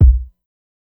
KICK_GUTER.wav